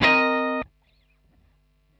Cm7_9.wav